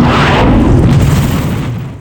rocket_blackbox_shoot_crit.wav